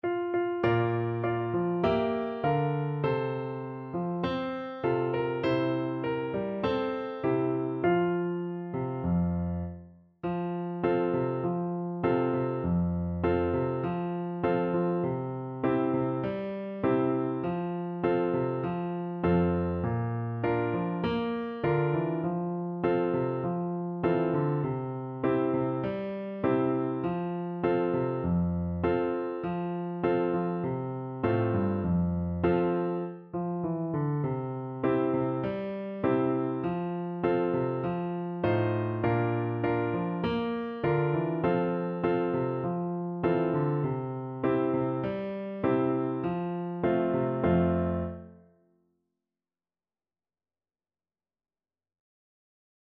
Play (or use space bar on your keyboard) Pause Music Playalong - Piano Accompaniment Playalong Band Accompaniment not yet available transpose reset tempo print settings full screen
Moderato
4/4 (View more 4/4 Music)
F major (Sounding Pitch) C major (French Horn in F) (View more F major Music for French Horn )